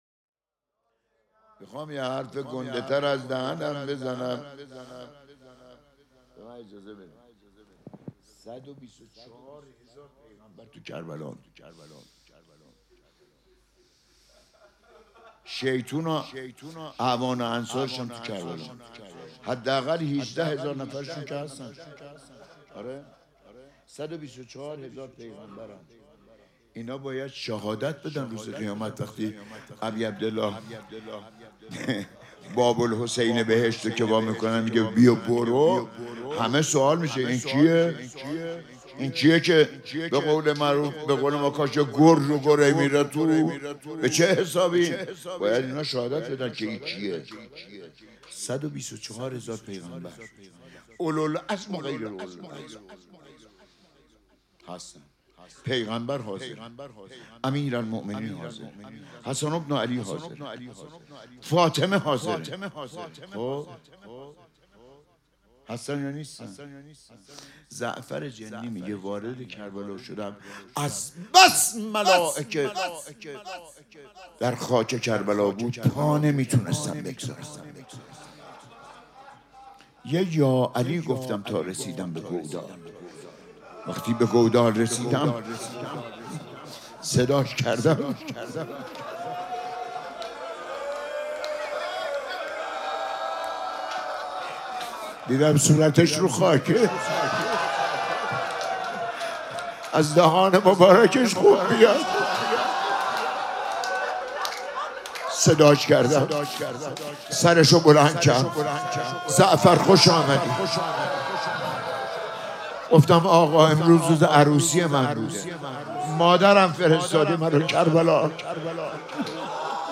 روضه
مراسم عزاداری شام شهادت حضرت زینب سلام‌الله‌علیها پنجشنبه ۲۷ دی ماه ۱۴۰۳ | ۱۵ رجب ۱۴۴۶ حسینیه ریحانه الحسین سلام الله علیها